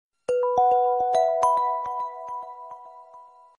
Kategorie SMS